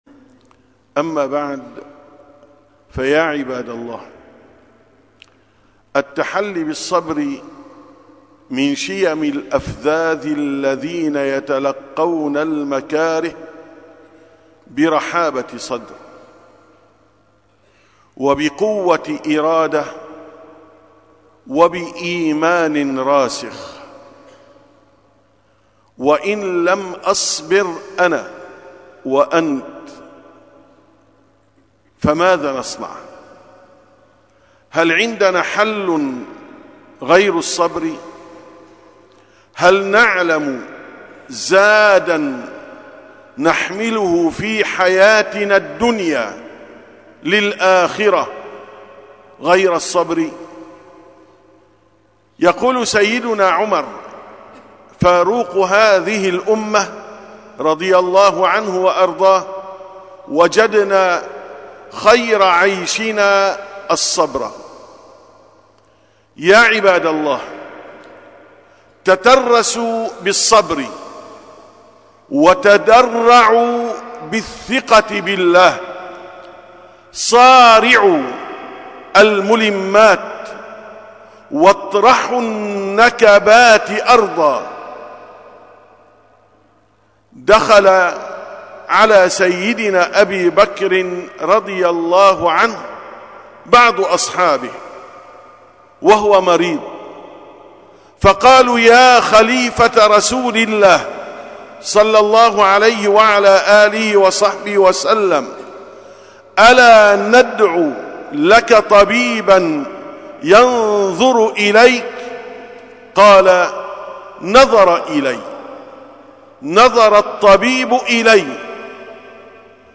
682ـ خطبة الجمعة: رزقنا على الله تعالى